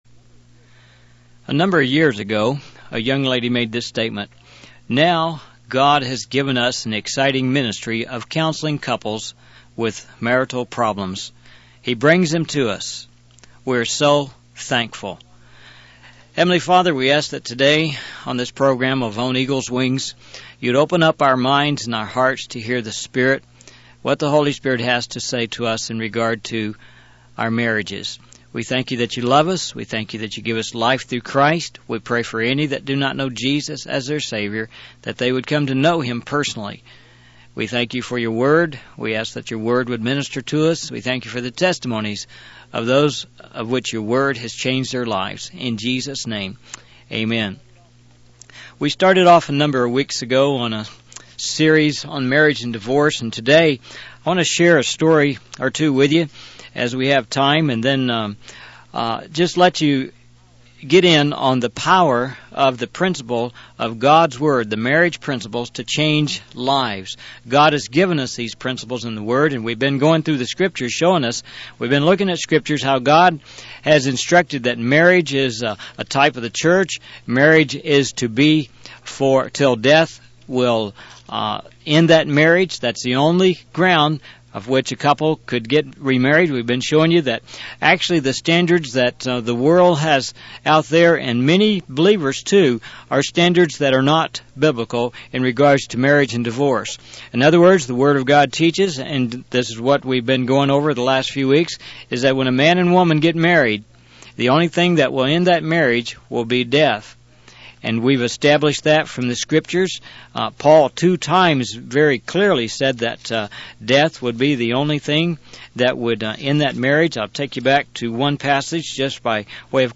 In this sermon, the speaker shares a personal testimony of attending a seminar where the principles of Scripture were taught and applied to life.